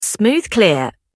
smooth_clear.ogg